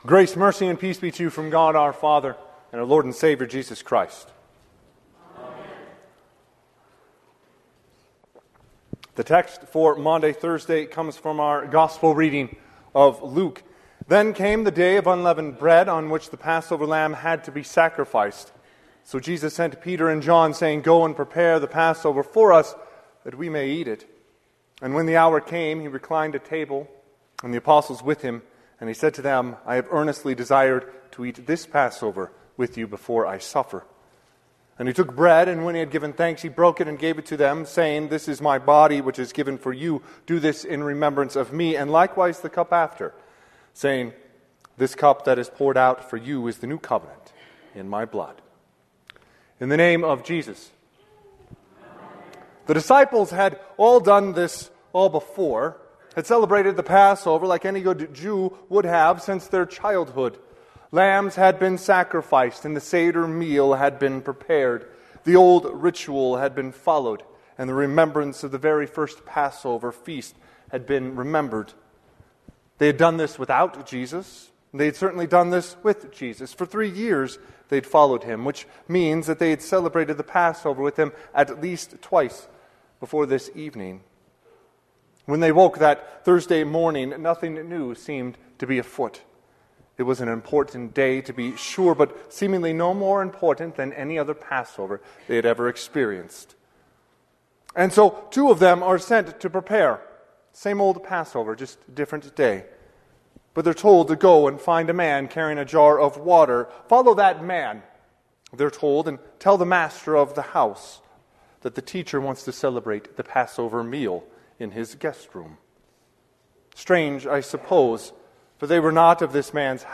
Sermon – 4/17/2025